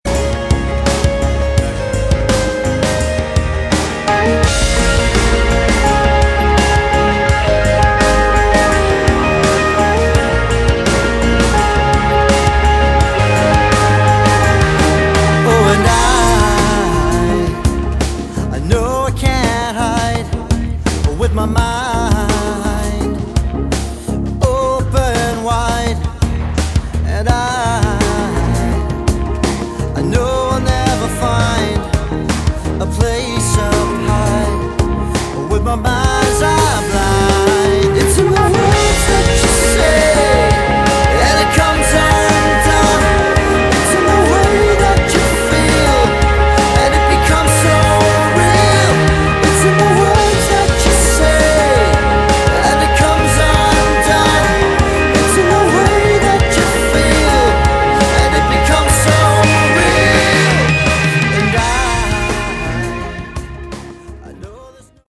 Category: Progressive Melodic Rock
vocals, drums, keys
keys, programming, sound design
bass guitar
electric and acoustic guitars